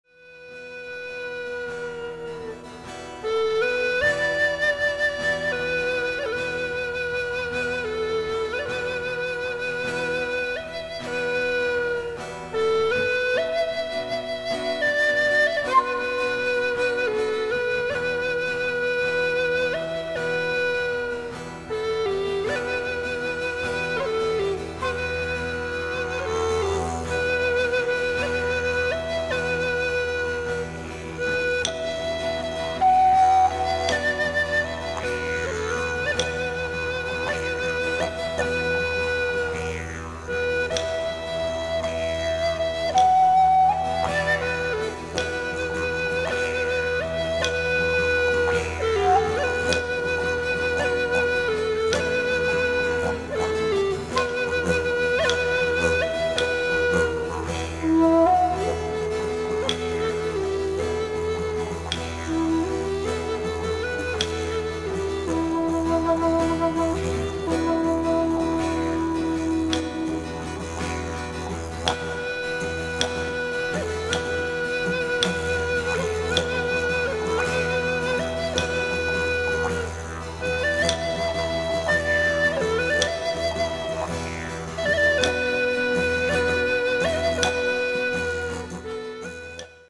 Auftritt am Freitag, 6. Juli 2001 am Swizzeridoo um 20.00 Uhr
Didgeridoo, Indianer-Flöte, Blues Harp, Gitarre und Percussion (Djembe, Ocean Drum, Clap Sticks)
Soundfiles vom Konzert: